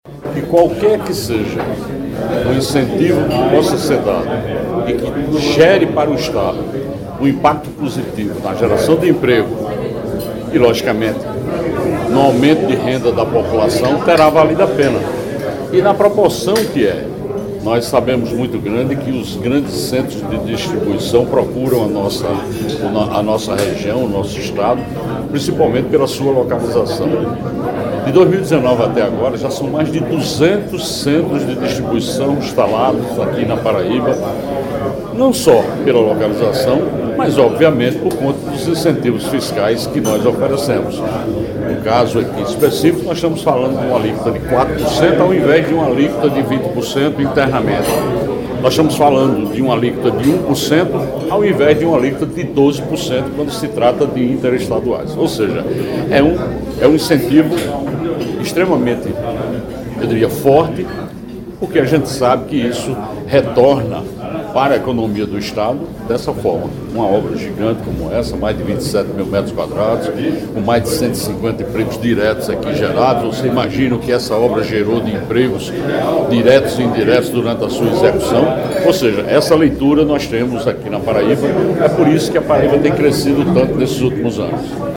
Governador e Prefeito de CG se encontram no evento de inauguração